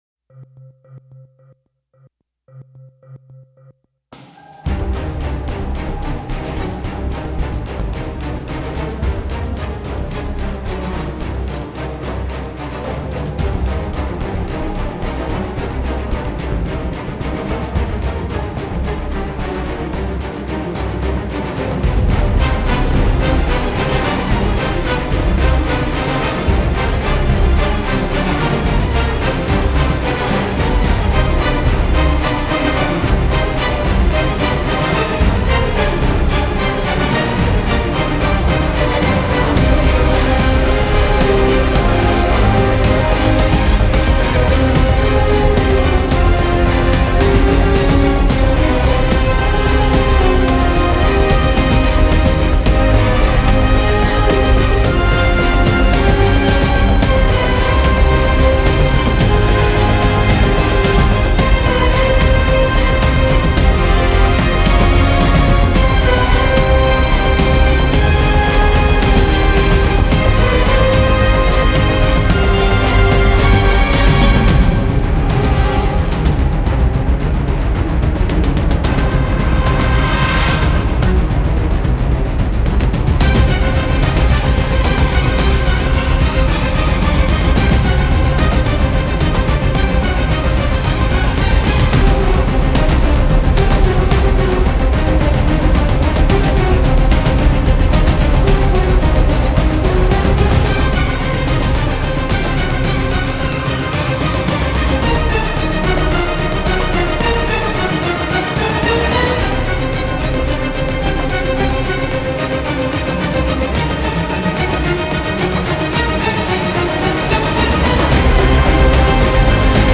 Genre : FilmsGames, Film Scores